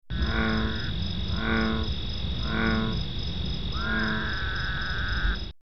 Frog